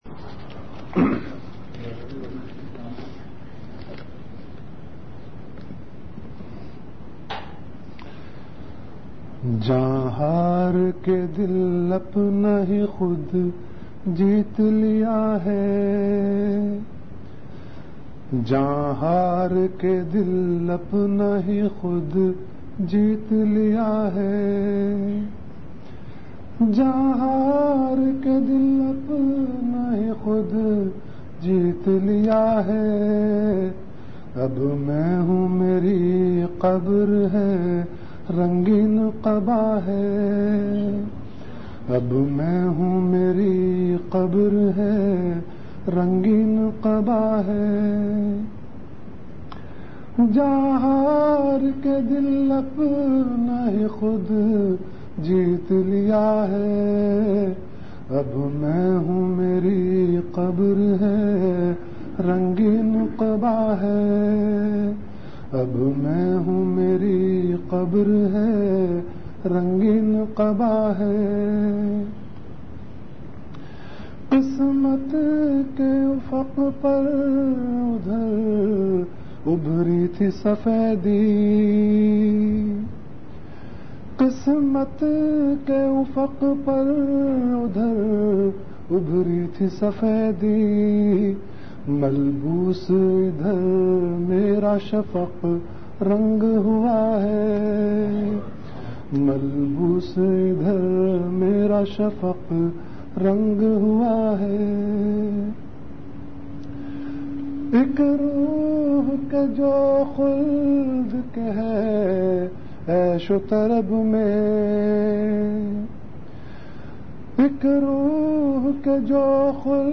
Delivered at Home.
Category Majlis-e-Zikr
Event / Time After Isha Prayer